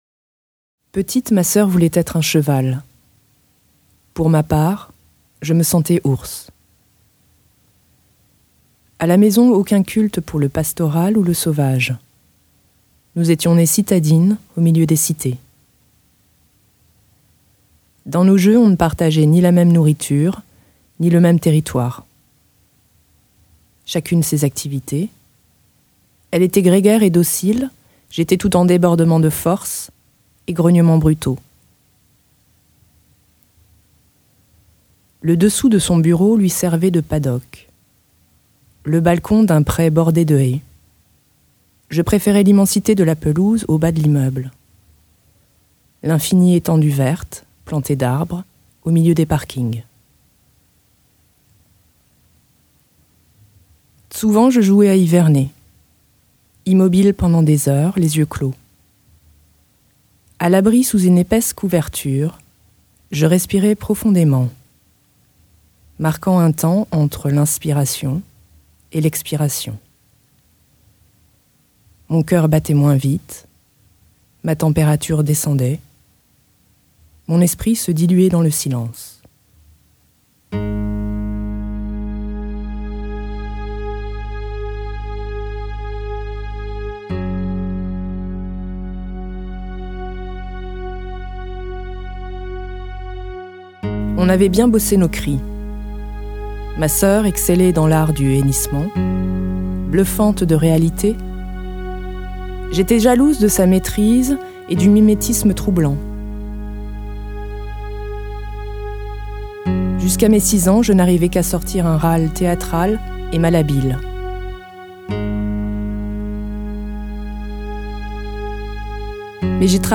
Vocal and sound piece, 33 min.